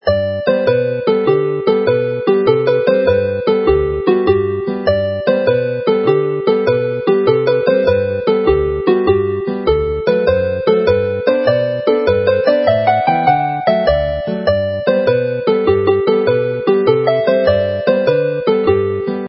New Year's Eve jig